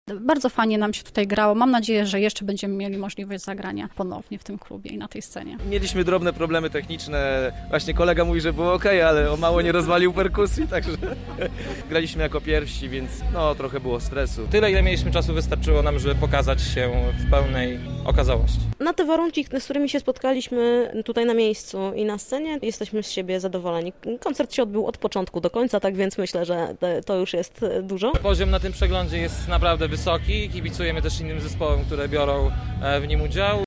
Wykonawcy opowiedzieli nam o swoich wrażeniach z występu: